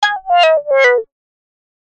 / F｜演出・アニメ・心理 / F-10 ｜ワンポイント マイナスイメージ_
感情：汗 04タンタタタン